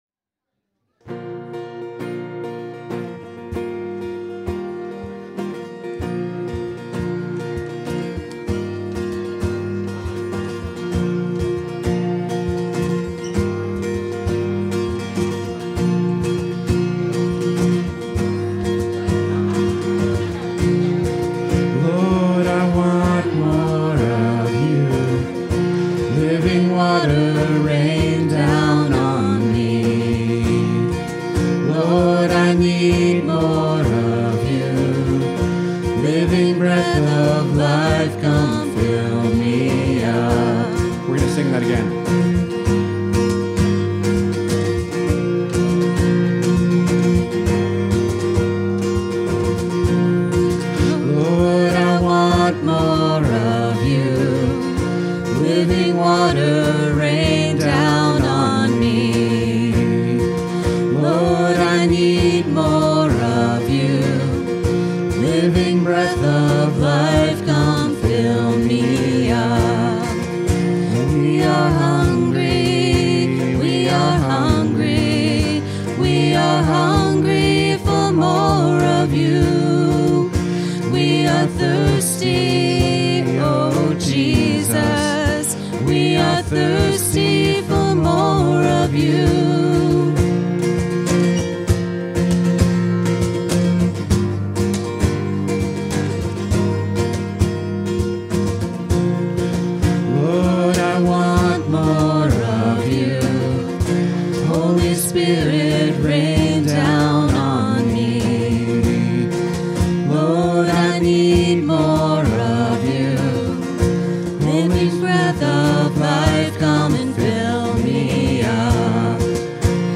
Worship 2025-08-24